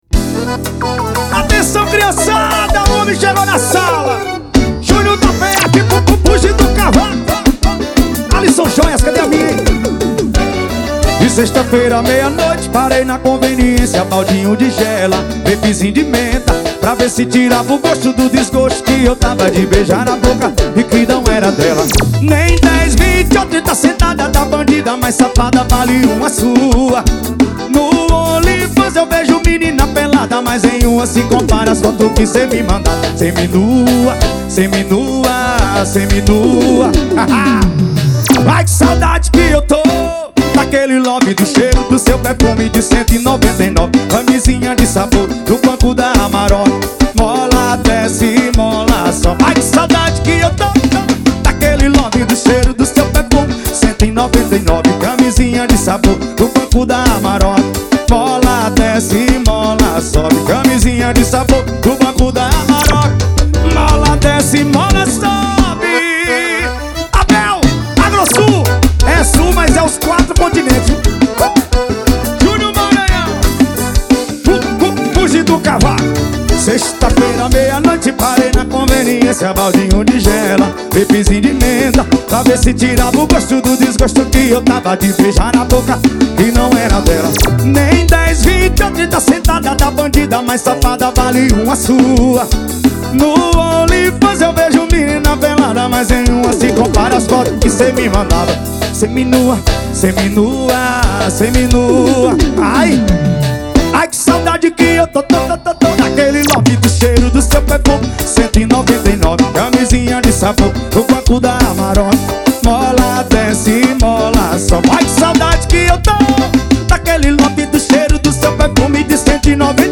2024-02-14 18:42:51 Gênero: FORRO Views